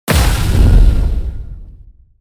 spell-impact-3.ogg